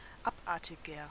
Ääntäminen
Ääntäminen Tuntematon aksentti: IPA: /ˈapʔaʁtɪɡɐ/ Haettu sana löytyi näillä lähdekielillä: saksa Käännöksiä ei löytynyt valitulle kohdekielelle. Abartiger on sanan abartig komparatiivi.